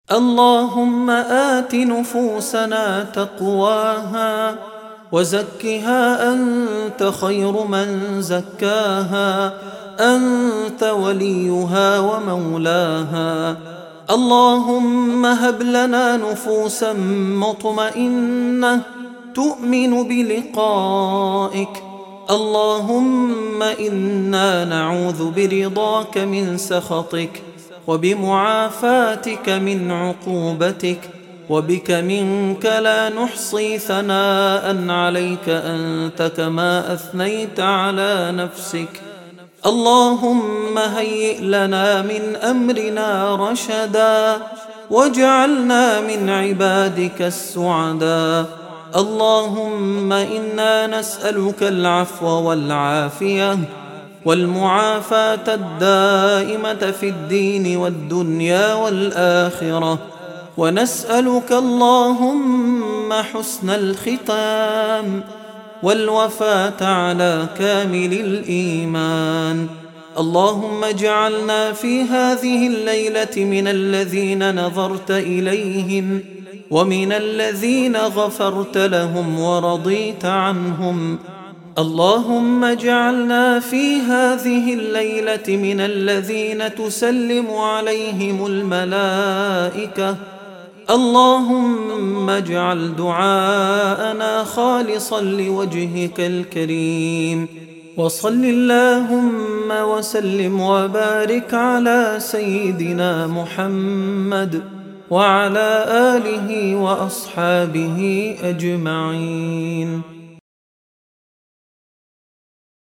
دعاء خاشع ومؤثر مليء بالمناجاة والتضرع إلى الله تعالى. يتضمن طلب العفو والعافية والهداية والرضا، مع التوسل بأسماء الله وصفاته.